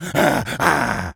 gorilla_angry_08.wav